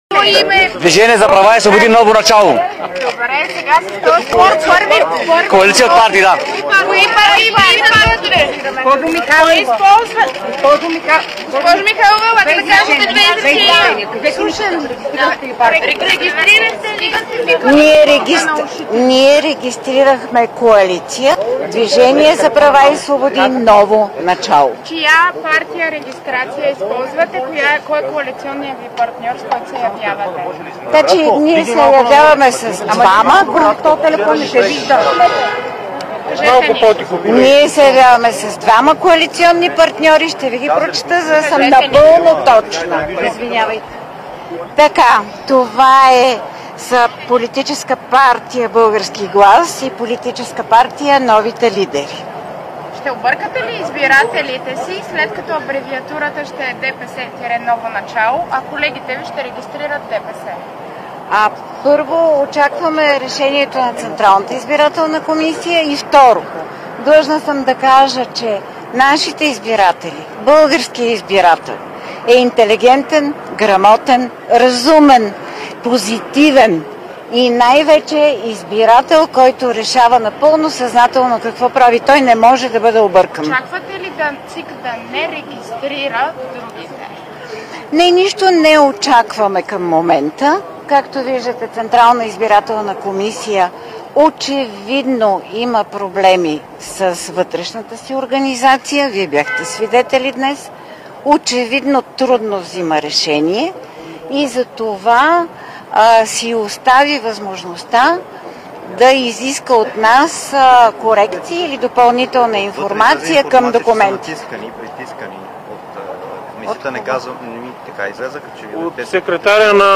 11.20 - Среща на председателя на ГЕРБ Бойко Борисов с координатори на ГЕРБ и  кметове на общини в област Бургас. - директно от мястото на събитието (Бургас)
Директно от мястото на събитието